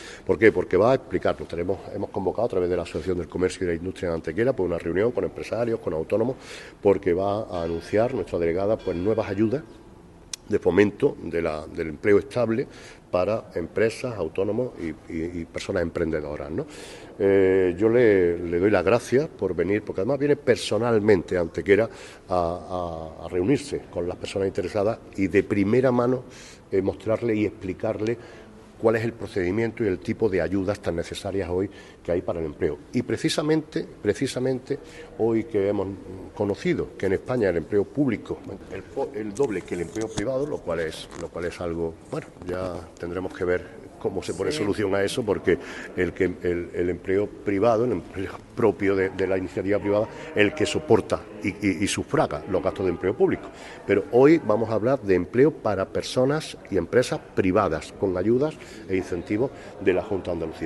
Durante la presentación de este paquete de ayudas para el empleo estable, celebrada en el salón de actos del MVCA ante una treintena de empresarios y representantes, la Delegada ha confirmado que se contemplan ayudas que oscilan entre los 3.500 y los 6.600 euros por cada contrato indefinido que formalicen las empresas y autónomos malagueños y que supongan un incremento neto de su plantilla fija.
Cortes de voz